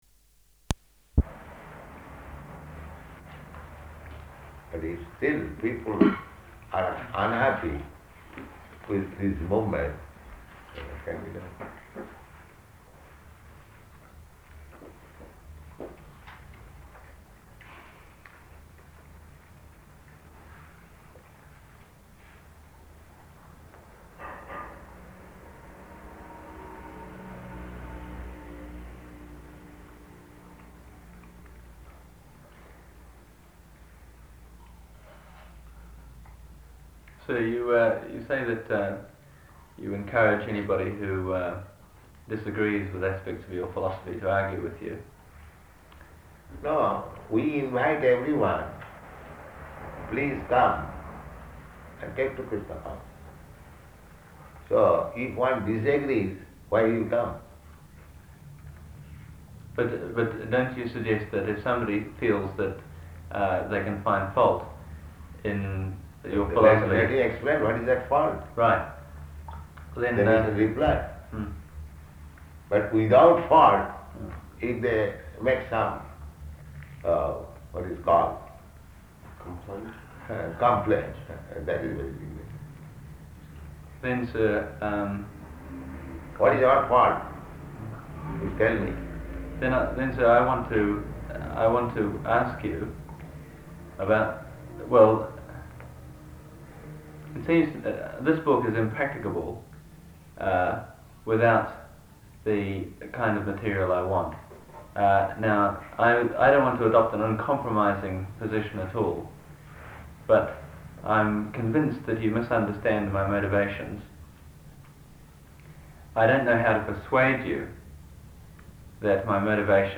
Conversation with Author
Conversation with Author --:-- --:-- Type: Conversation Dated: April 1st 1972 Location: Sydney Audio file: 720401R1.SYD.mp3 Prabhupāda: And if still people are unhappy with this movement, then what can be done?